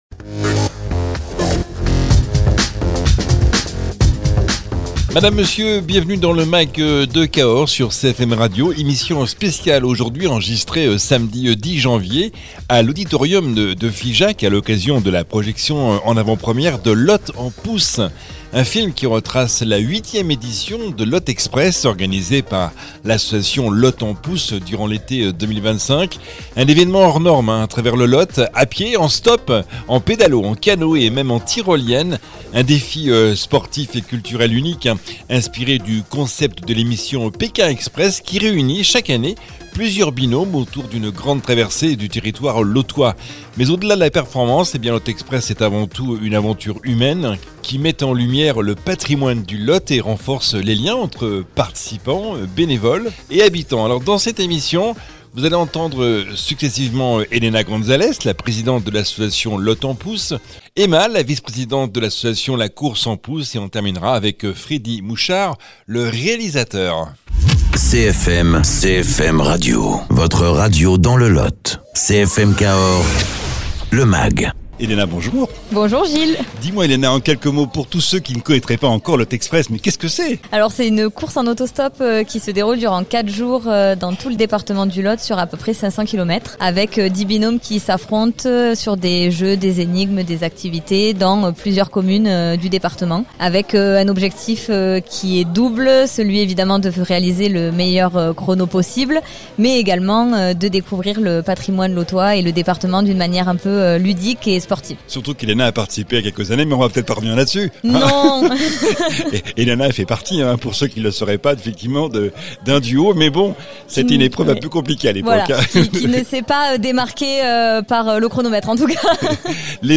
Émission spéciale enregistrée samedi 10 janvier à l’auditorium de Figeac, à l’occasion de la projection en avant-première de « Lot en Pouce », un film qui retrace la 8e édition de Lot Express, organisée par l’association La course en pouce durant l’été 2025.